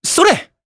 Evan-Vox_Attack3_jp.wav